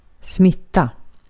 Uttal
Synonymer infektion infektera Uttal Okänd accent: IPA: /ˈsmɪtˌa/ Ordet hittades på dessa språk: svenska Ingen översättning hittades i den valda målspråket.